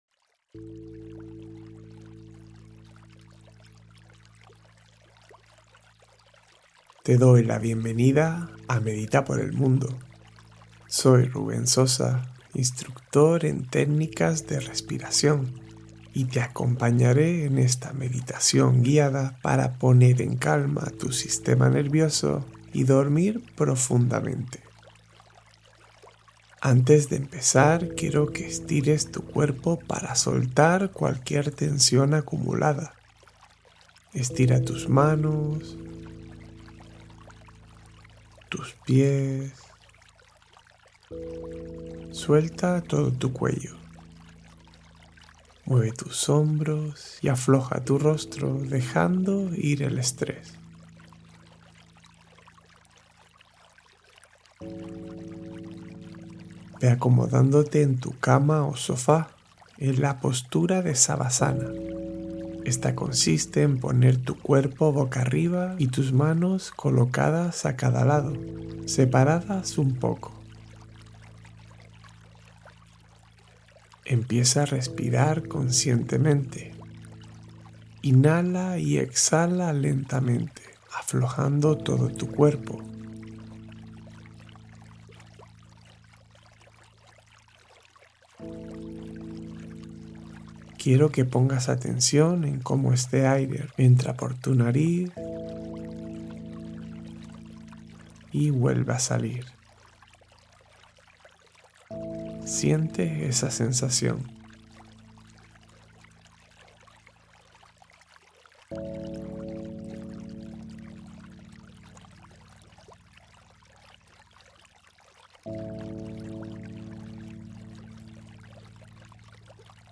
Duerme en Serenidad: Meditación Suave con Ley de Atracción